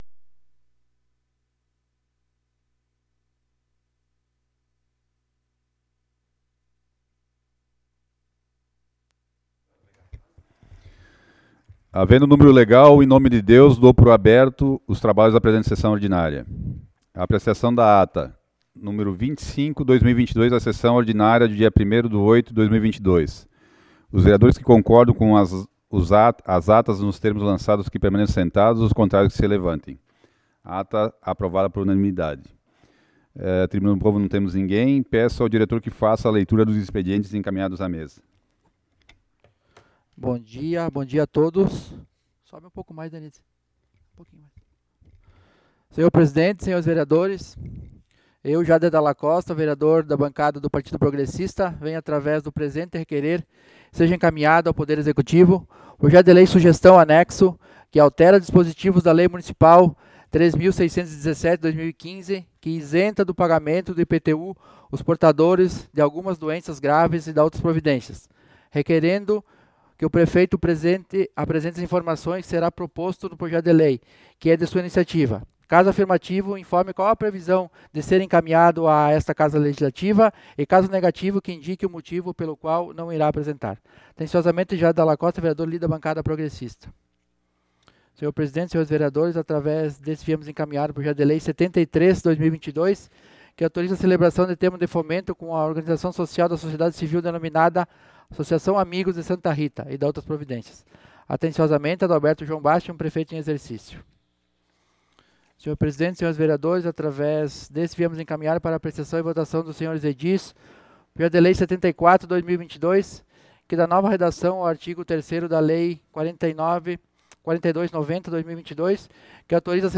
Sessão Ordinária do dia 08 de Agosto de 2022 - Sessão 26